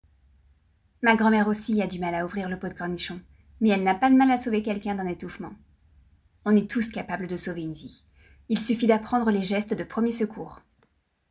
Bandes-son
- Soprano